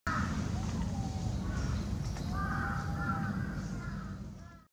アオーーアオーーと聞こえてきたときはビックリした。
鳴いたのも一度きり。